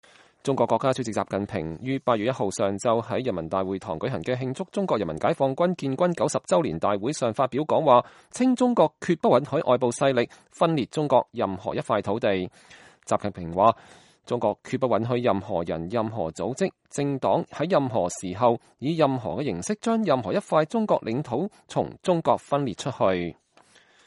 習近平在北京人民大會堂舉行的解放軍建軍90週年大會上發表講話 （2017年8月1日）